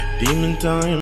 Play, download and share Demon2 original sound button!!!!
demon2.mp3